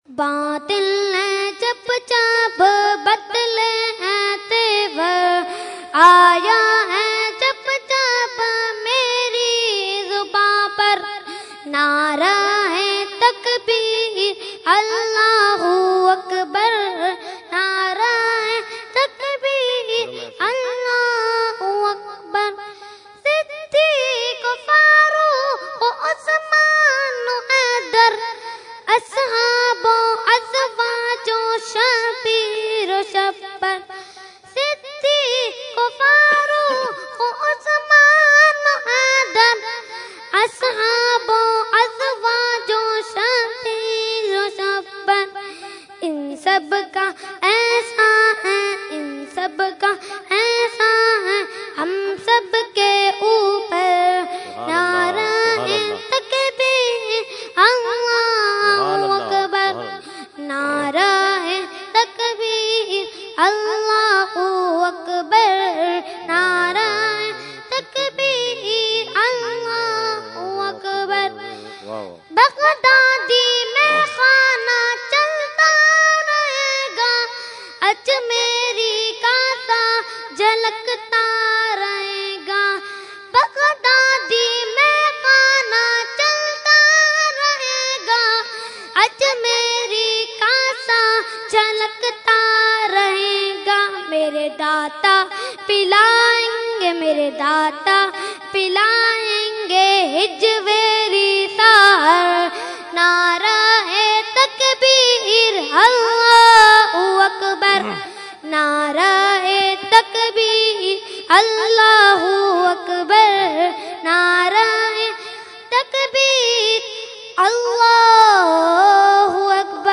Category : Hamd | Language : UrduEvent : Urs Ashraful Mashaikh 2015